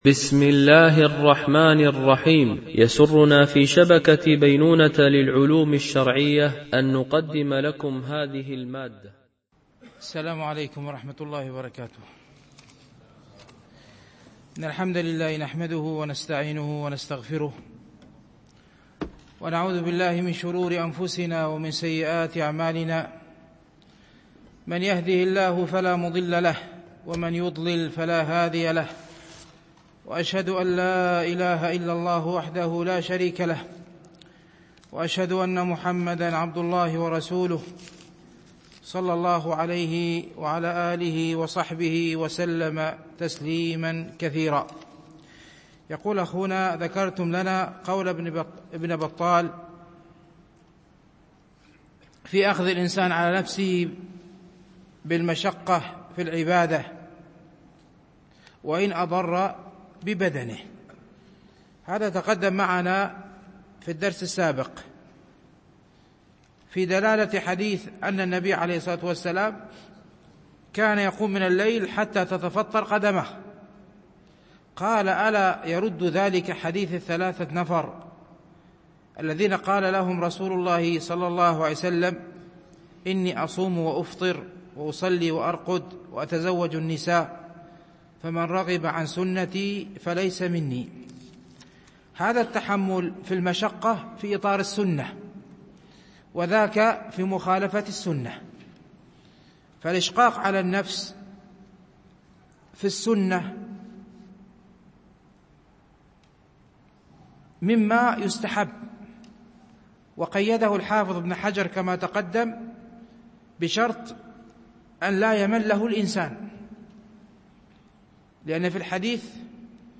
شرح رياض الصالحين - الدرس 302 ( الحديث 1170- 1173)